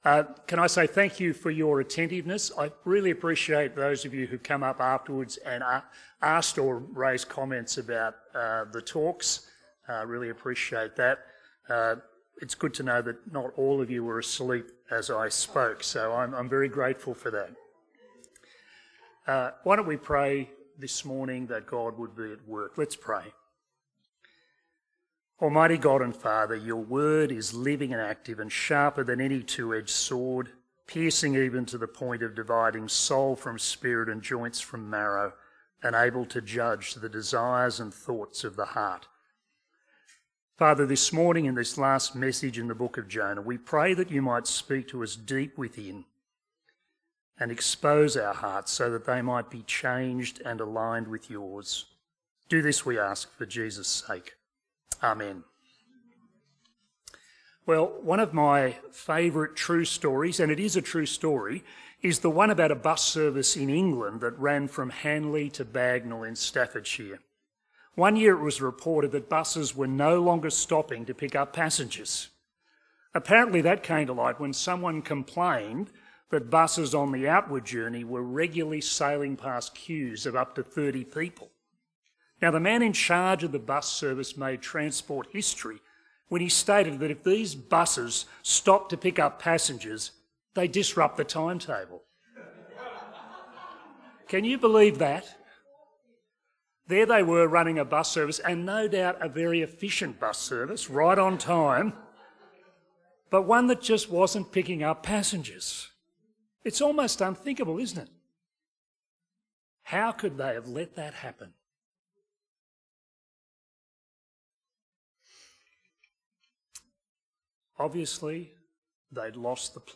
Sermons
Bible talk on Jonah 4. from the Mission Minded series